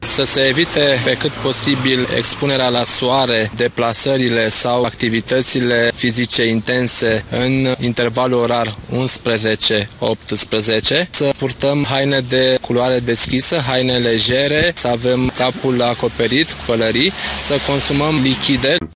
medic-sfaturi-canicula.mp3